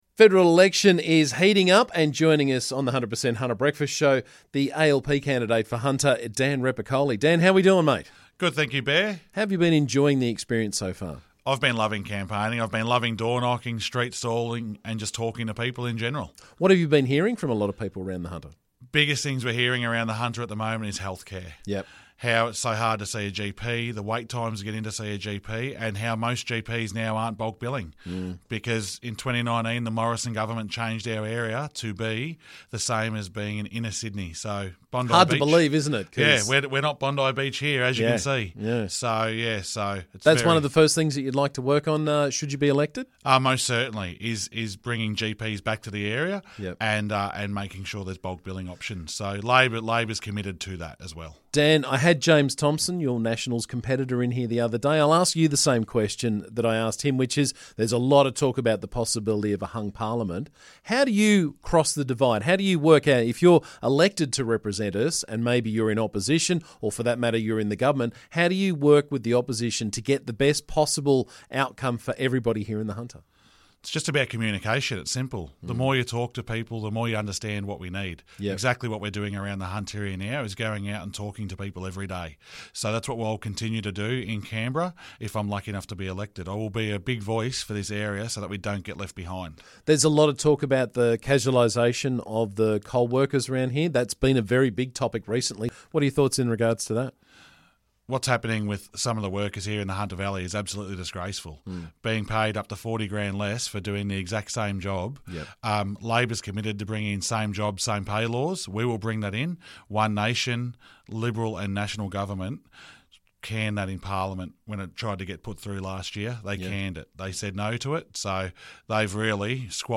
ALP candidate for Hunter, Dan Repacholi, was in the studio to talk about what he's hoping to improve in our region should he be elected.